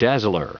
Prononciation du mot dazzler en anglais (fichier audio)
Prononciation du mot : dazzler